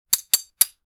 Click-sounds Sound Effects - Free AI Generator & Downloads